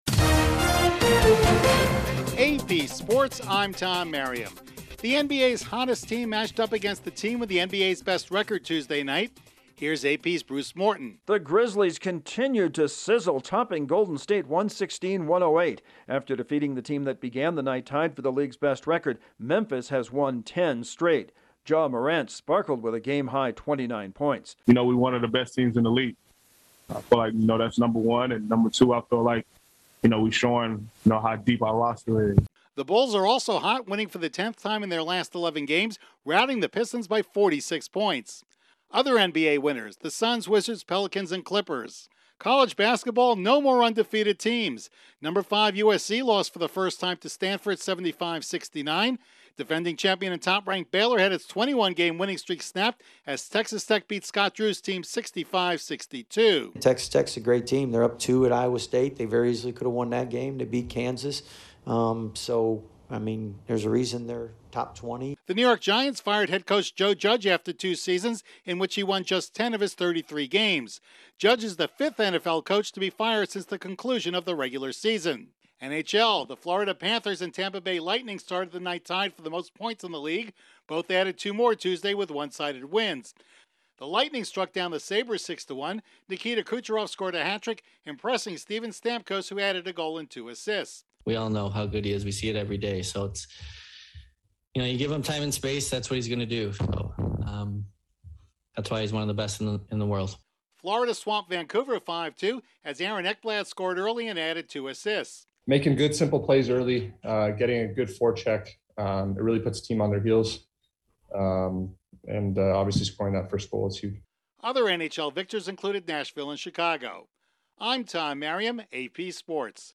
Sports News from the Associated Press / AP SportsWatch-Wednesday Sports Wrap